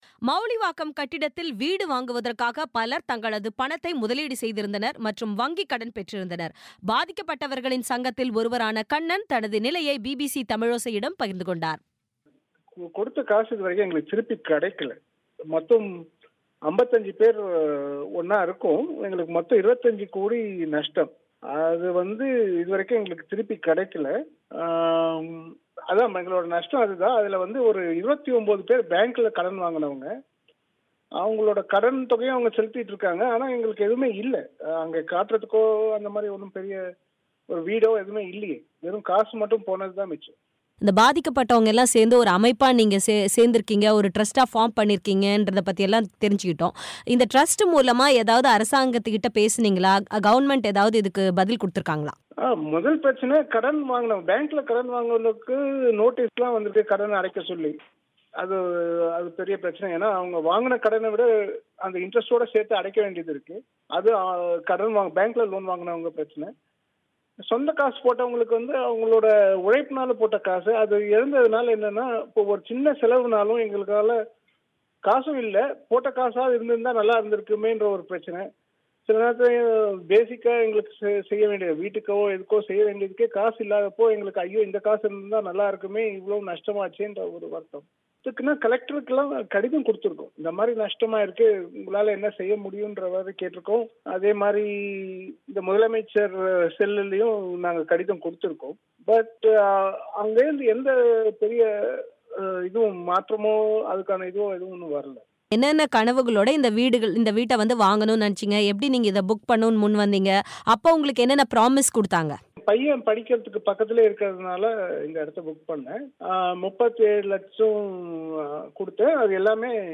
மவுலிவாக்கம் கட்டிடத்தில் வீடு வாங்கியதால் பாதிக்கப்பட்ட நபரின் பேட்டி